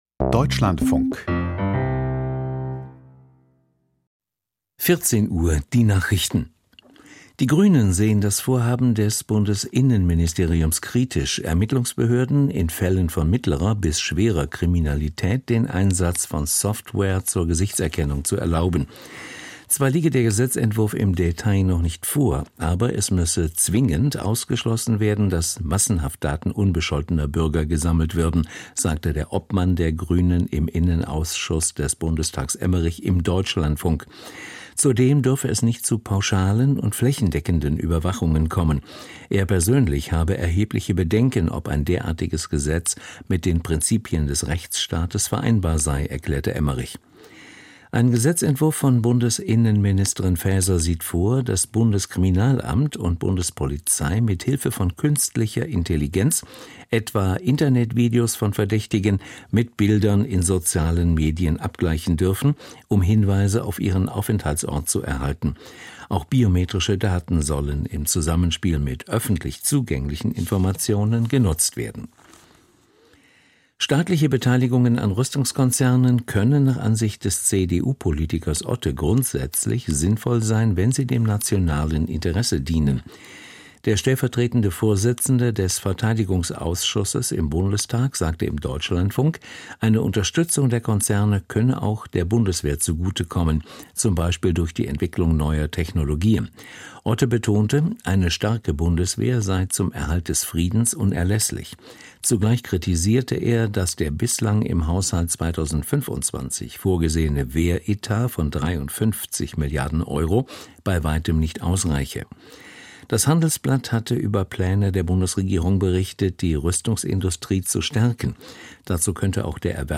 kath. Gottesdienst aus der Kirche St. Ansgar in Bremerhaven - Leherheide - 01.09.2024